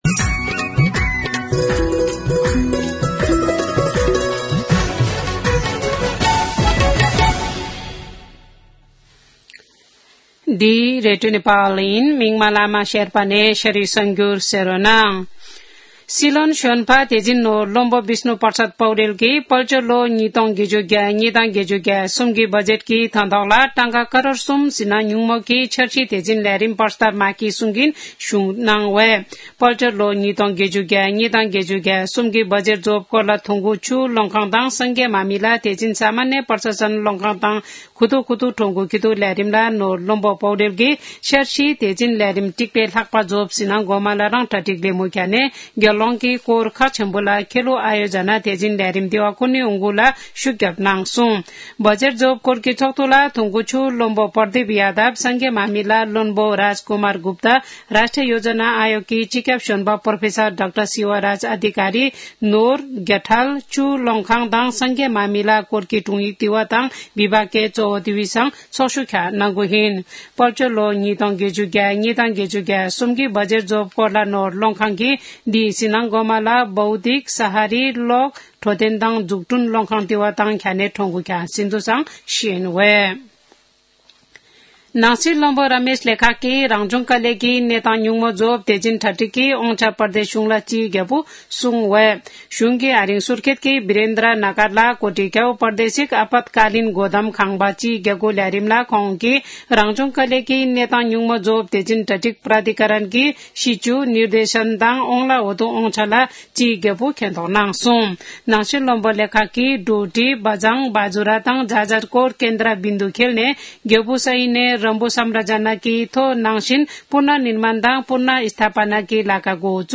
शेर्पा भाषाको समाचार : २६ फागुन , २०८१
Sherpa-News-25.mp3